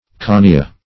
conia - definition of conia - synonyms, pronunciation, spelling from Free Dictionary
Conia \Co*ni"a\ (? or ?), n. [NL.